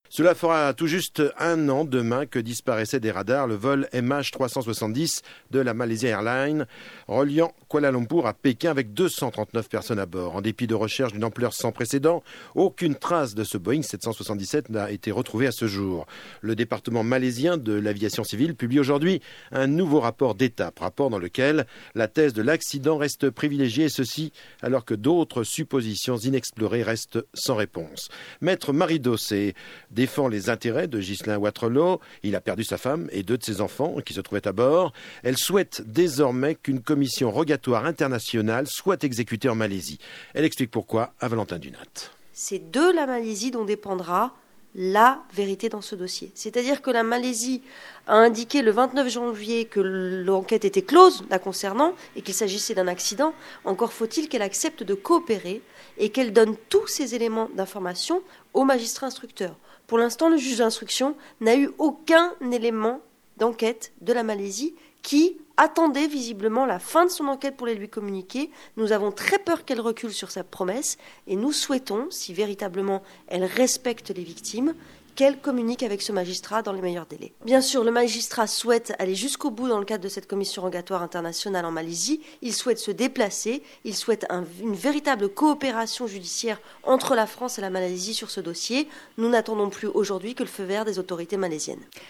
Diffusé sur France Inter le 7 mars 2015
Radio